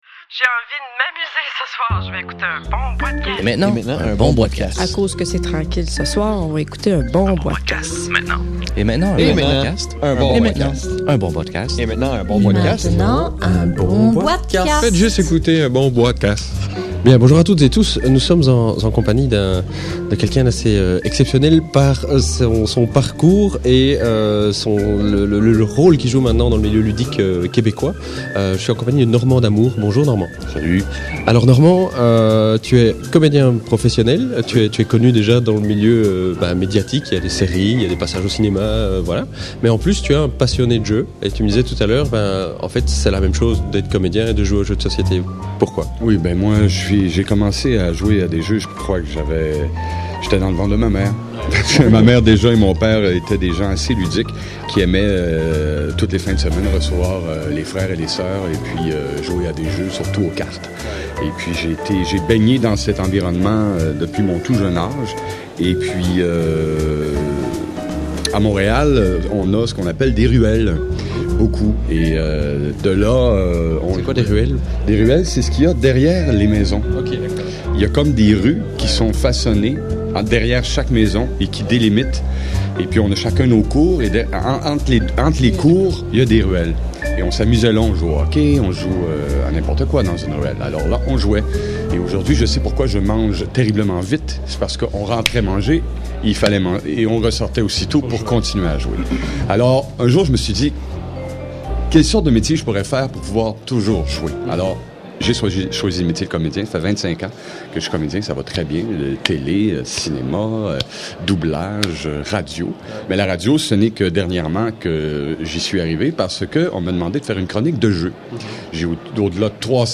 (enregistré lors de la Fiesta Ludique 2009 à Montréal/Québec)